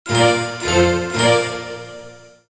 Outro Sound Effect
Use this short musical phrase for audio logos, games, applications, TikTok or YouTube videos, and more. It can be used for ending, level completed, and failed sounds.
Genres: Sound Logo
Outro-sound-effect.mp3